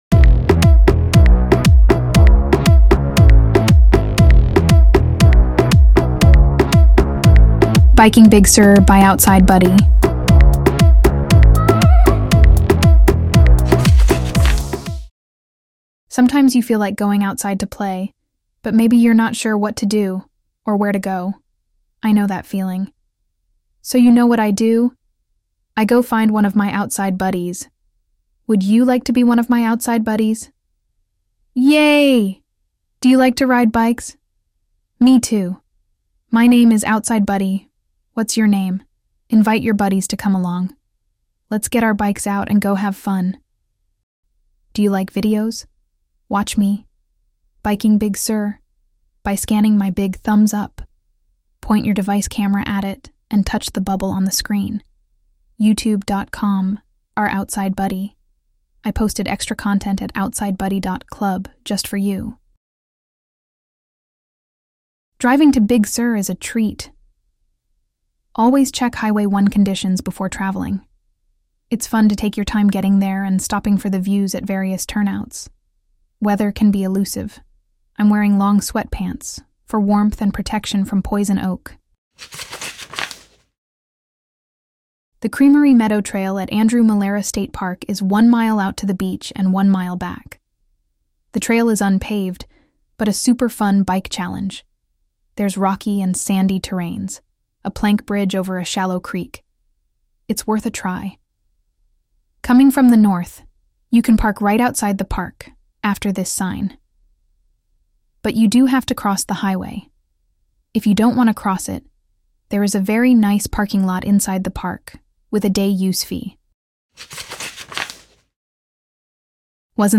Free AudioBook!